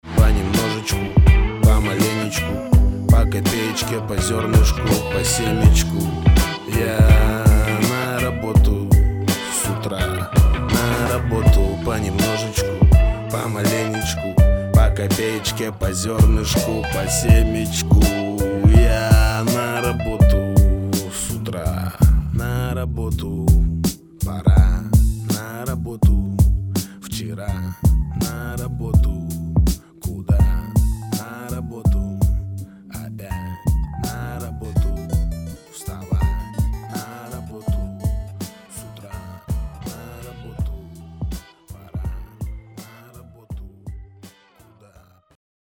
• Качество: 320, Stereo
мужской вокал
грустные
русский рэп
мотивирующие